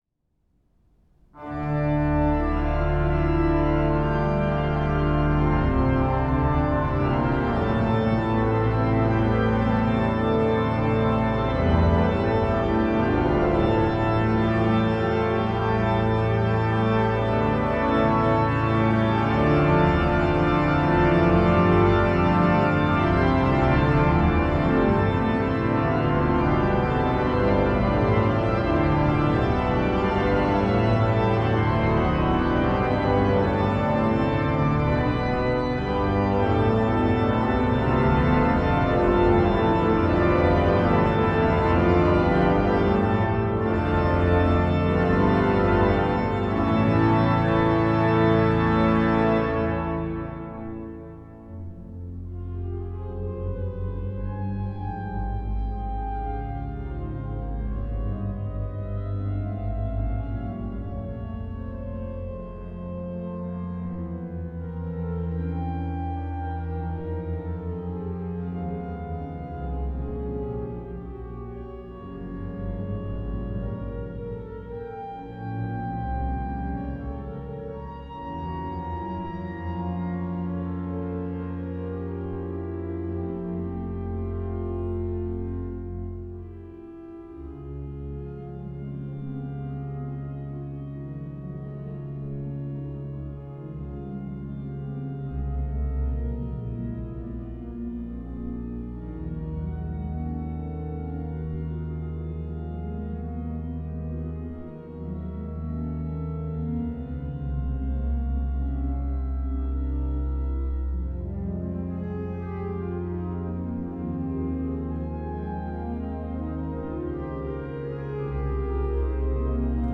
an der Orgel der Ulmer Pauluskirche.
Sein Kompositionsstil ist der Spätromantik verhaftet mit durchaus überschaubaren Formen und einer zwingenden harmonischen Logik.